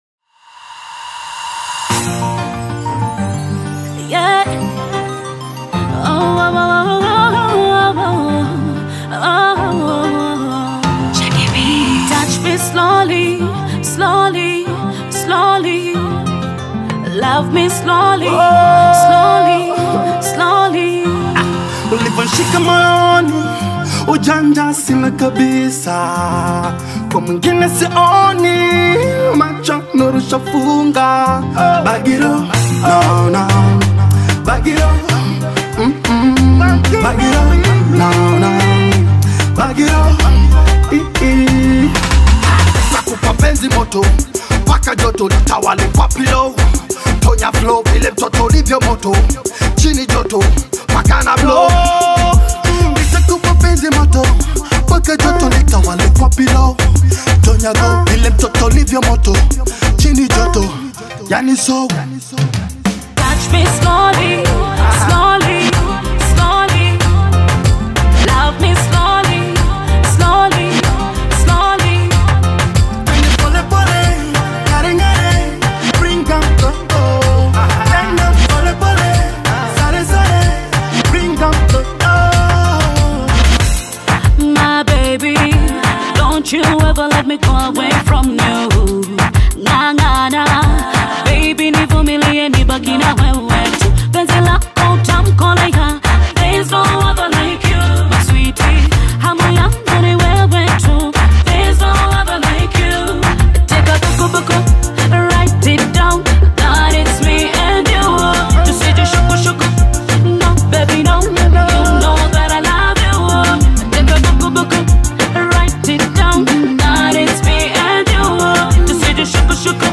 soulful Afro-Pop love anthem